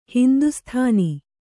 ♪ hindusthāni